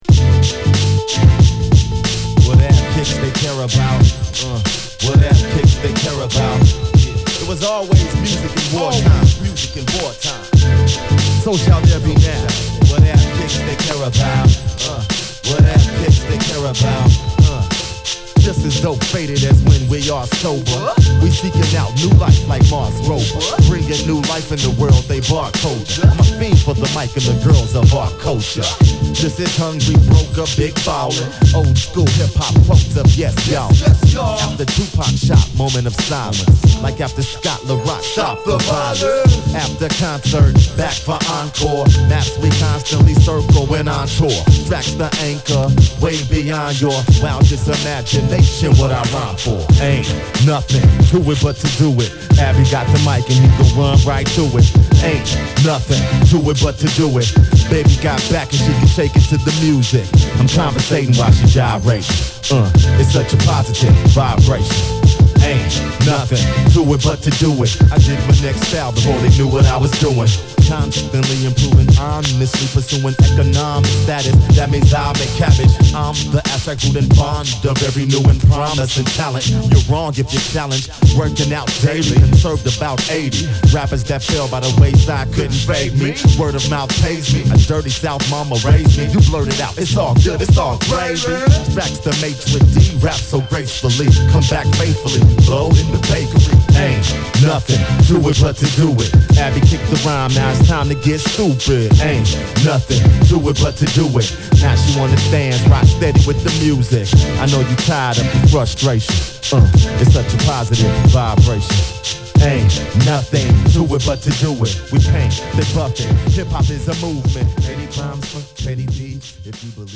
West Coast Underground Hip Hop!!